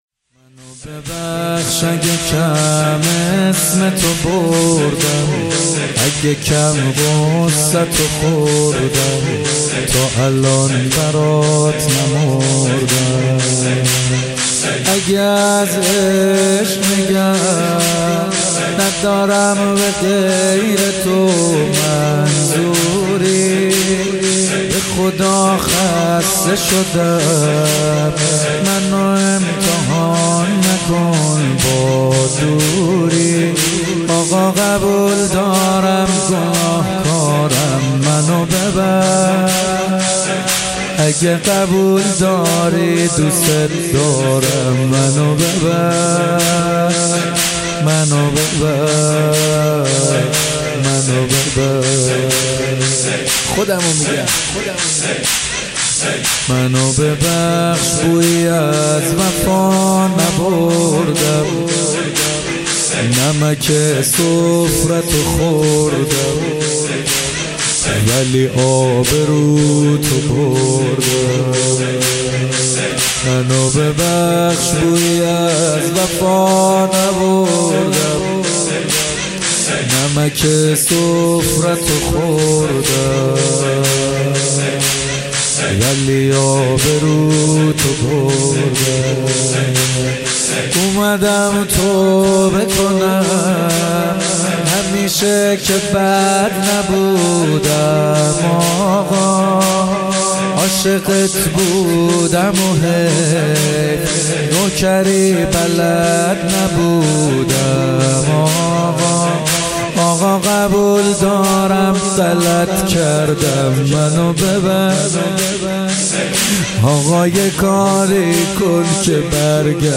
در ادامه می‌توانید صوت مداحی و مناجات‌خوانی این مراسم را بشنوید: